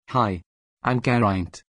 Male
English - Wales